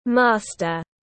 Master /ˈmɑː.stər/